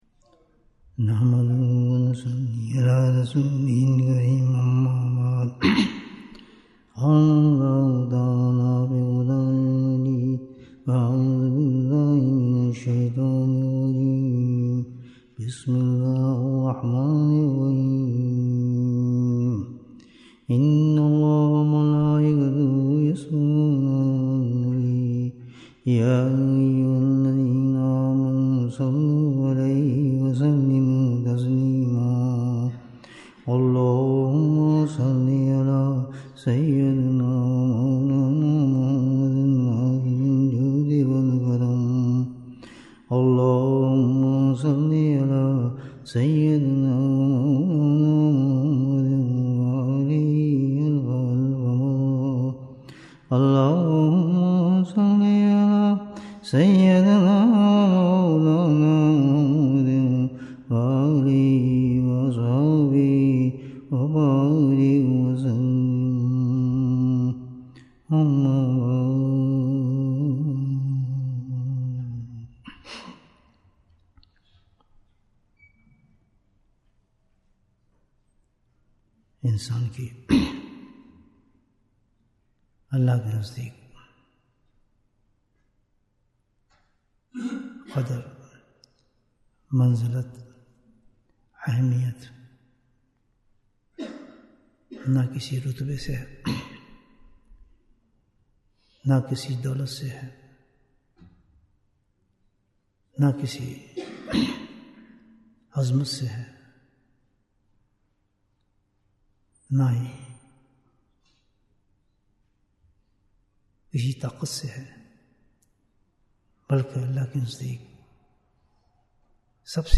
Bayan, 38 minutes 9th May, 2024 Click for English Download Audio Comments How Can We Judge Our Iman?